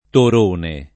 [ tor 1 ne ]